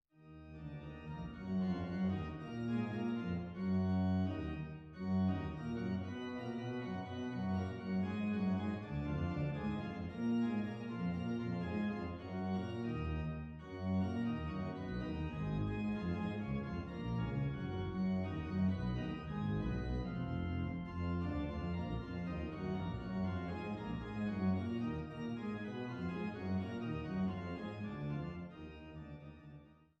Weise-Orgel in Gräfenroda